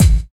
Wu-RZA-Kick 56.wav